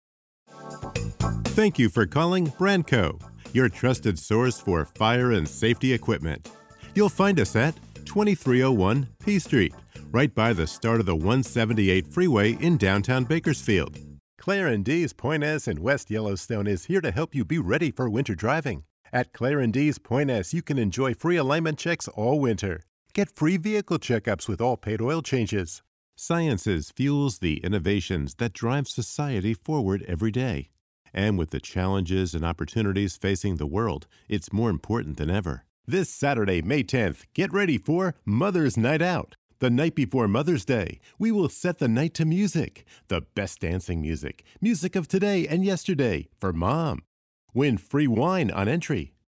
I am a male voiceover talent with over 20 years of VO experience . I have a professional home studio to send you clean files in either wav or mp3.
English - USA and Canada
Middle Aged